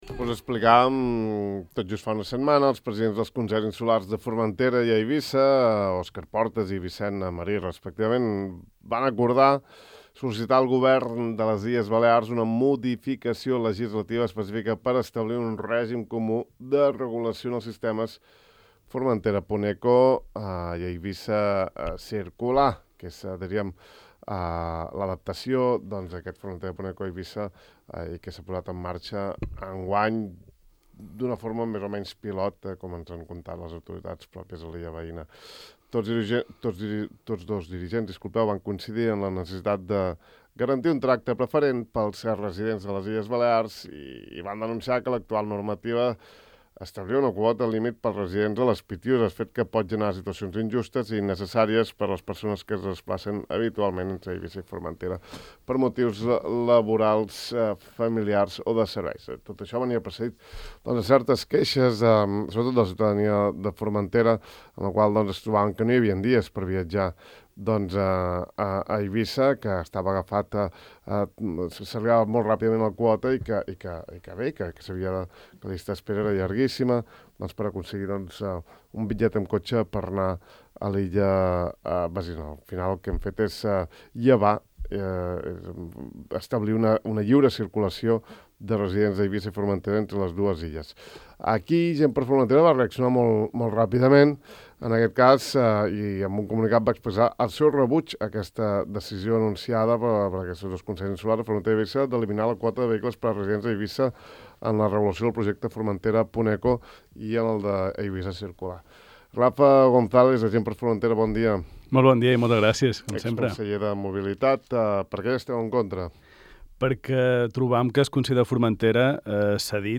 Sobre aquest assumpte n’ha parlat ja el conseller Rafa Gonzàlez al De far a far de Ràdio Illa, tot asseverant que ‘el Consell de Formentera ha passat d’escoltar el Consell d’Entitats al Consell d’Eivissa’.